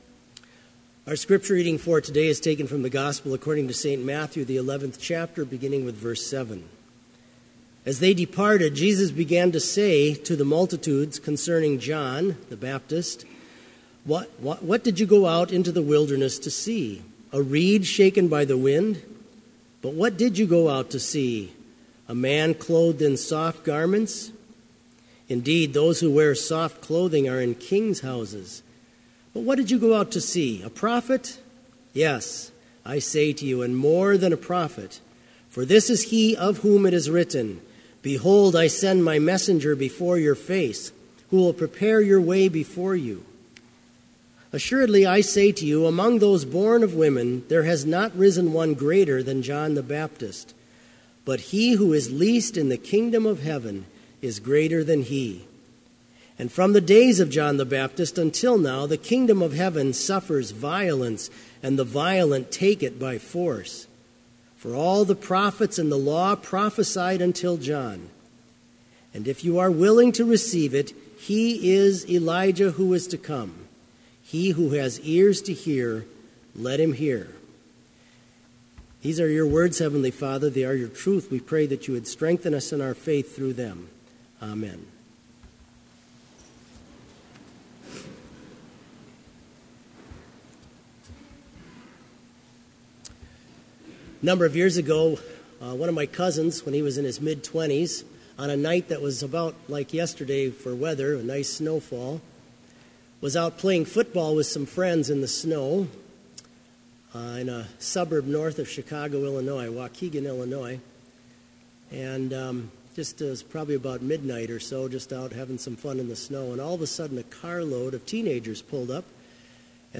Complete service audio for Chapel - December 12, 2016